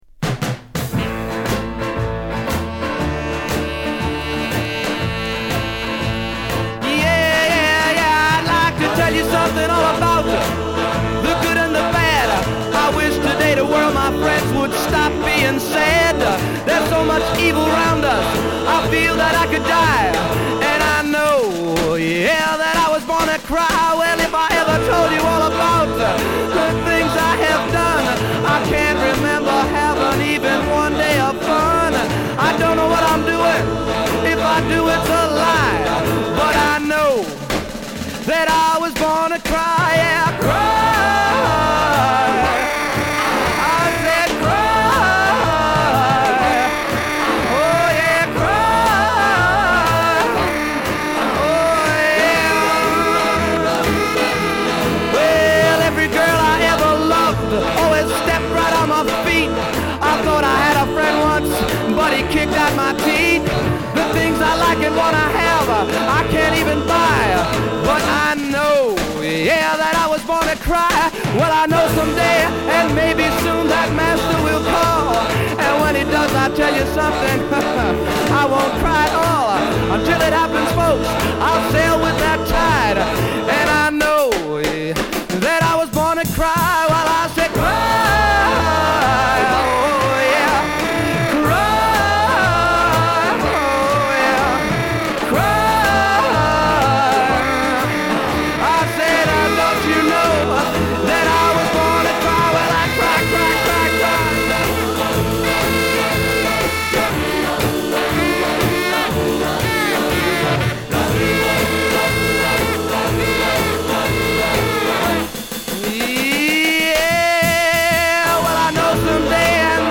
Стиль :Rock & Roll, Doo Wop